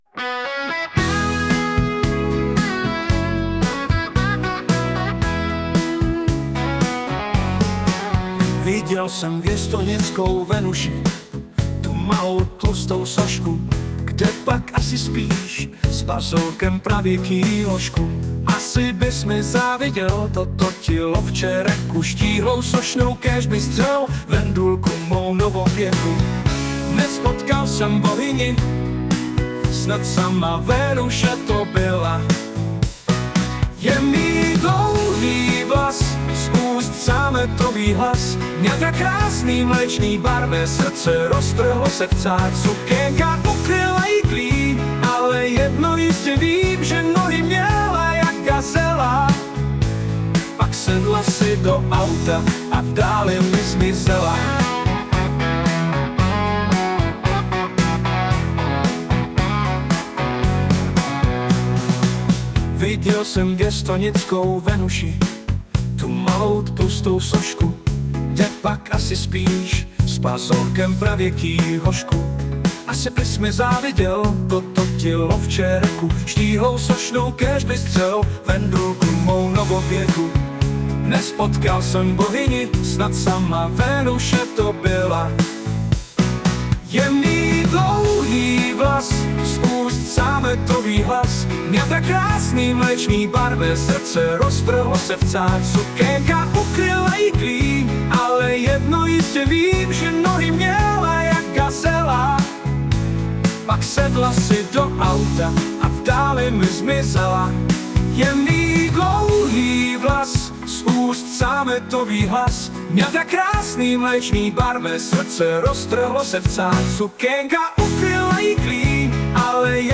* hudba, zpěv: AI